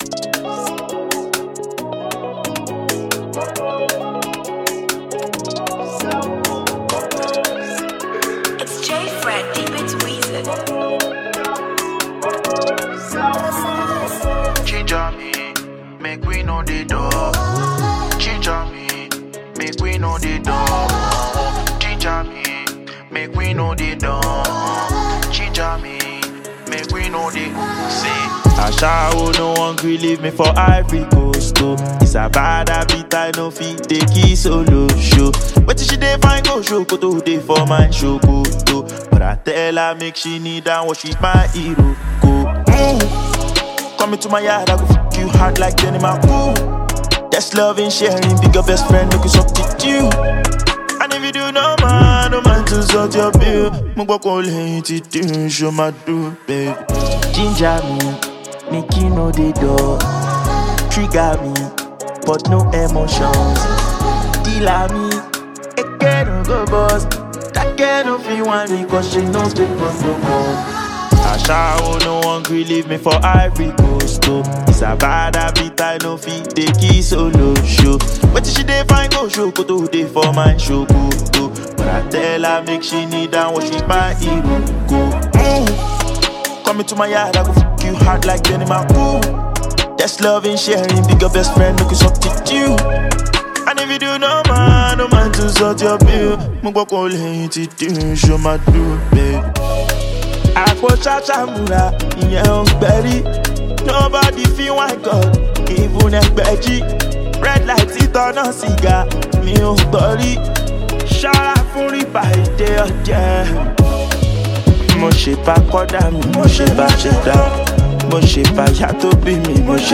Nigerian Afrobeat sensation